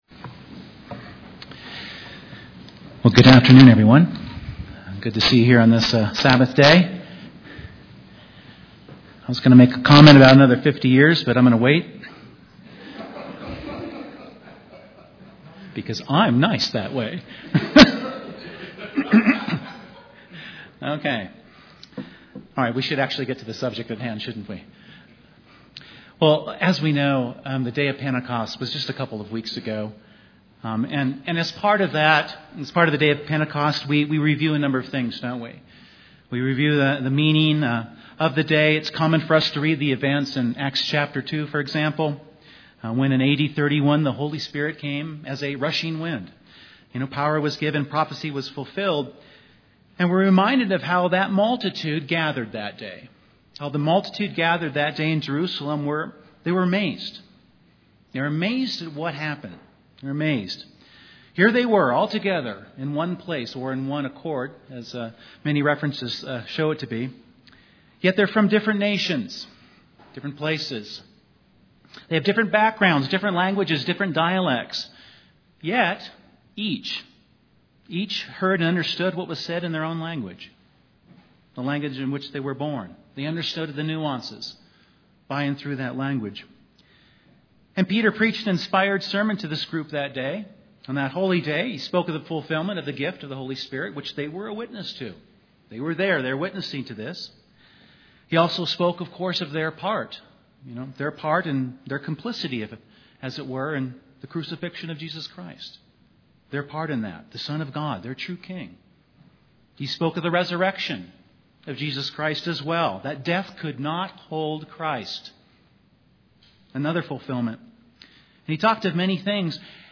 On the Day of Pentecost in AD 31, about 3,000 were baptized and added to the church. This sermon focuses on 4 things that Peter mentions they had in common and for which they set their minds and hearts to do thereafter (Acts 2:42); things for which we should have in common too.